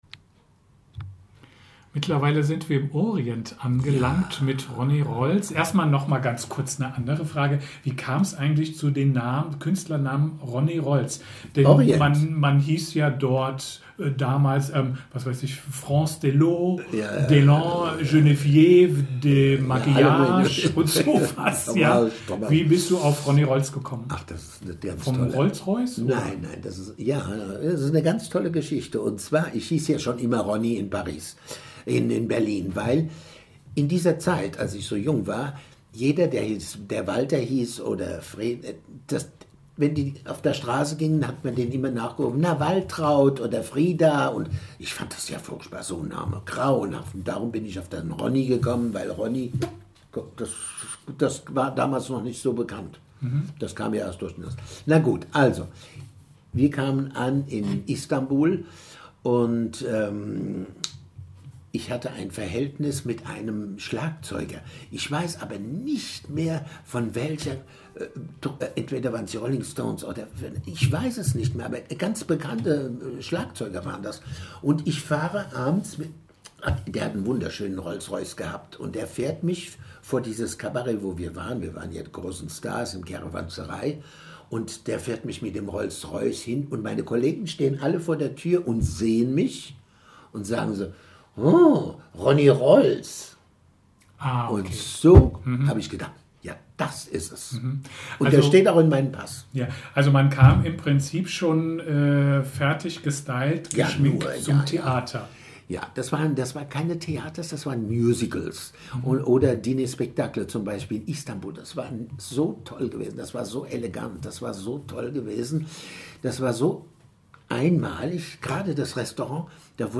Teil 4 des Interviews